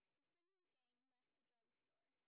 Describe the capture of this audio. sp18_train_snr20.wav